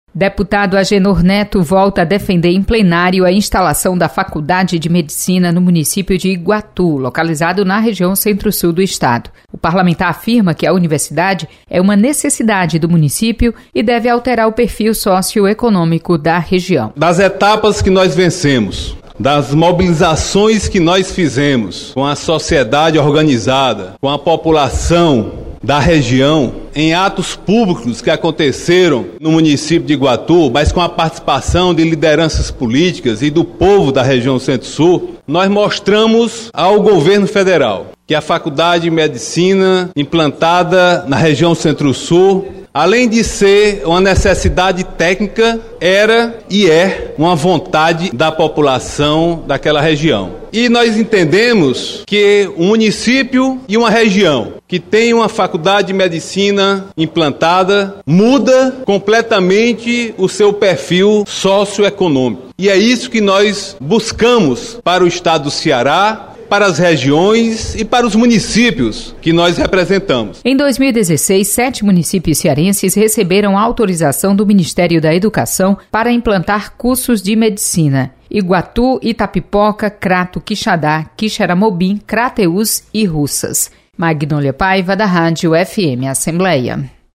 Deputado Agenor Neto volta a defender instalação de faculdade de Medicina em Iguatu. Repórter